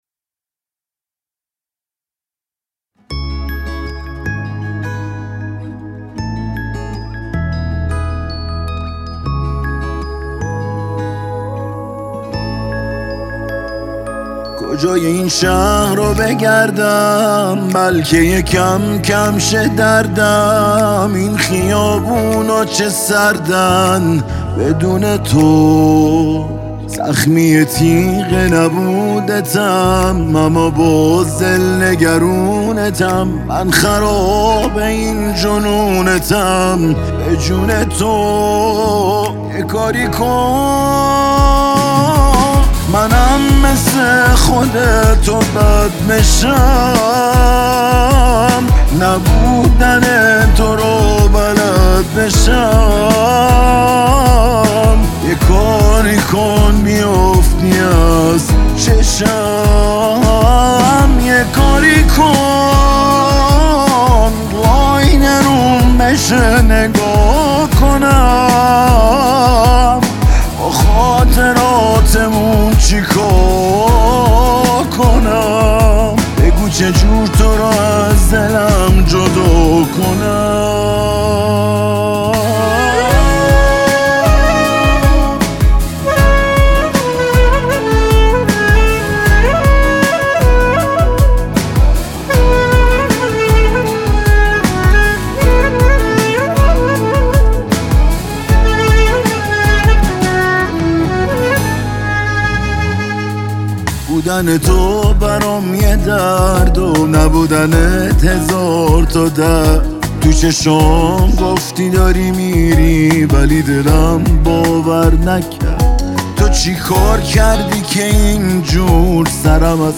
پاپ عاشقانه عاشقانه غمگین